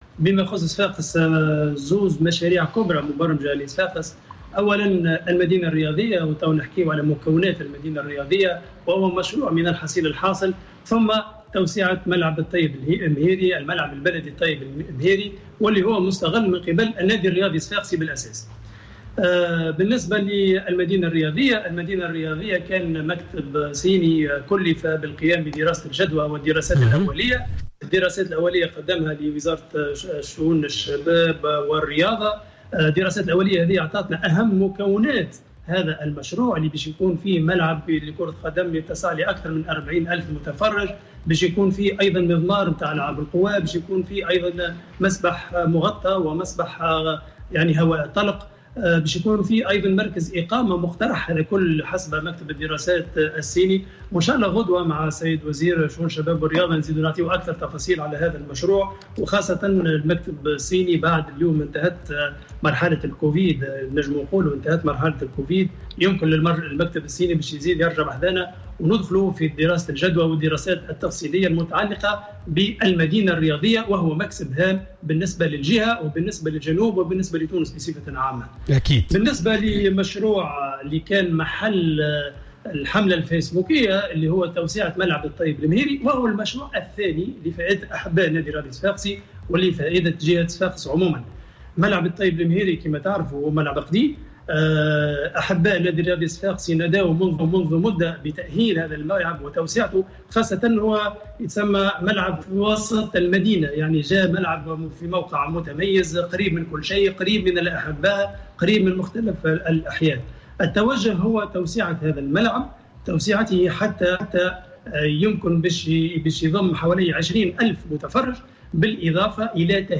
والي صفاقس يتحدث عن إجراءات مشروع توسعة ملعب الطيب المهيري و المدينة الرياضية
تدخل والي صفاقس السيد أنيس الوسلاتي، اليوم في برنامج "cartes sur table " على راديو الجوهرة اف ام للحديث عن المشاريع العمومية التي سيتم انشائها في مدينة صفاقس و المتمثلة في توسيع ملعب الطيب المهيري بالاضافة الى انشاء مدينة رياضية و التي ستكون مكسب كبير للجهة.